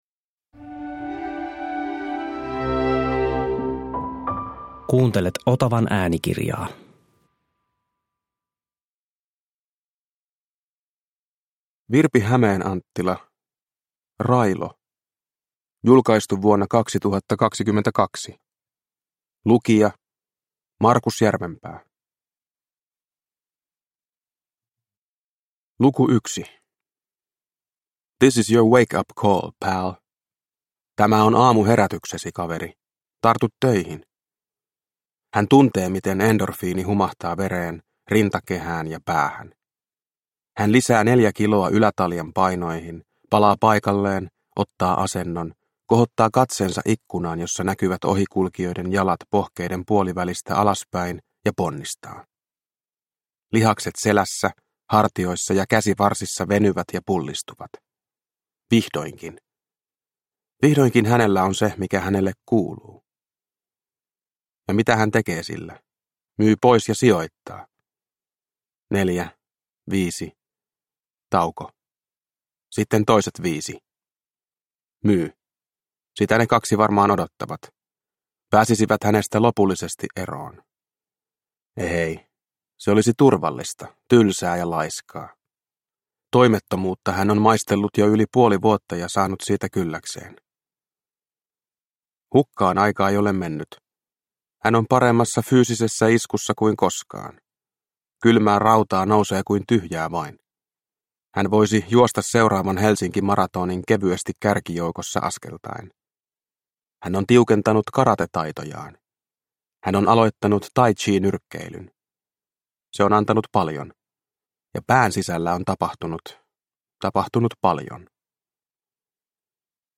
Railo – Ljudbok – Laddas ner